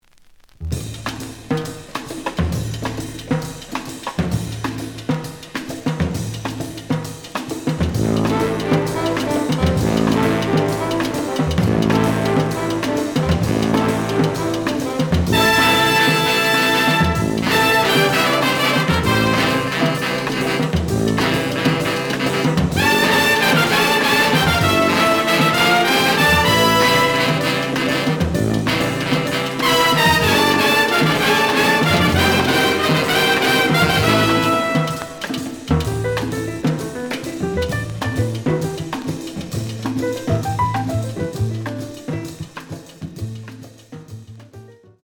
The audio sample is recorded from the actual item.
●Genre: Jazz Other